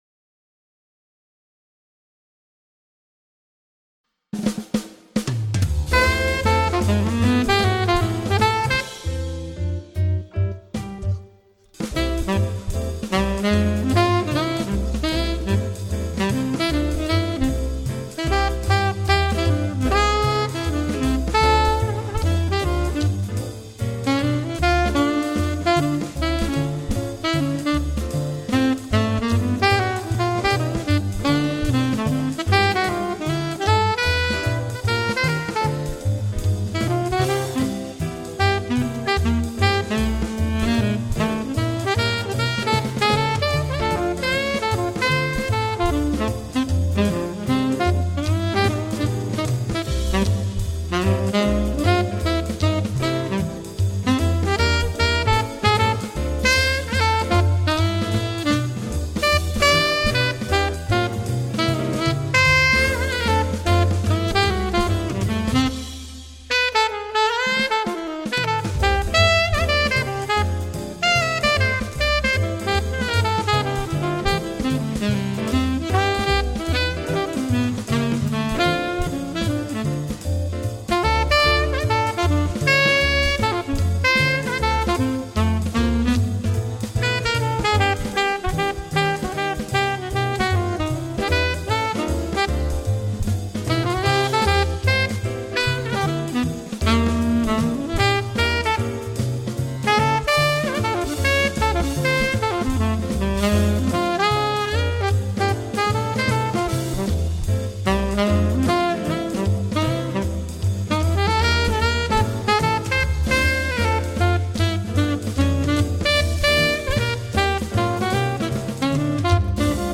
12 Jazz Etudes Based on Popular Standards